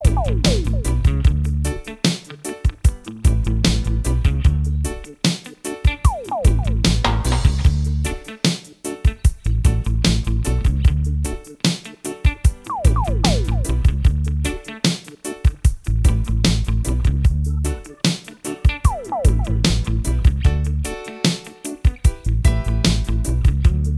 Minus Main Guitar Reggae 3:33 Buy £1.50